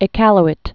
(ĭ-kăl-ĭt, ē-käl-ēt)